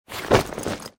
Звуки сумки, ранца
Тяжелый рюкзак упал на ковер или диван